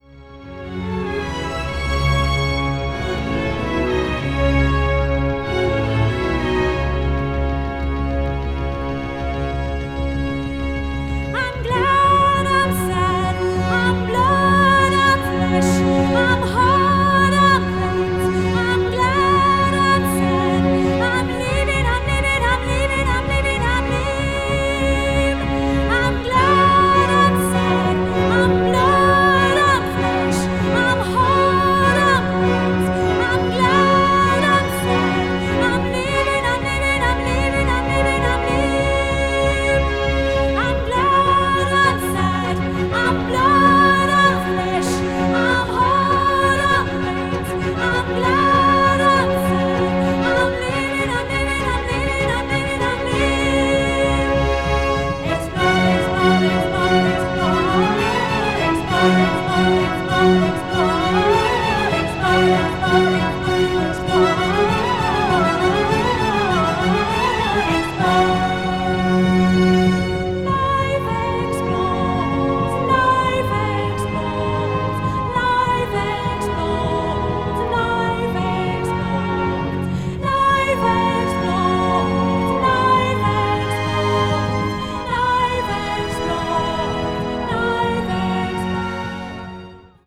media : EX+/EX+,EX+/EX+(わずかにチリノイズが入る箇所あり)